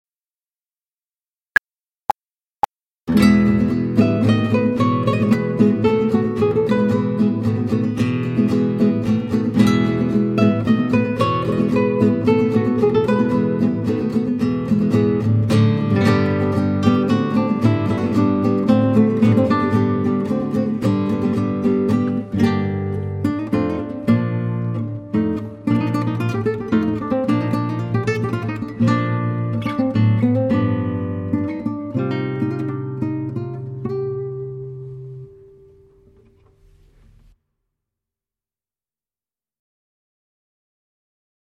Type de guitare guitare classique
• Instrumentation : Guitare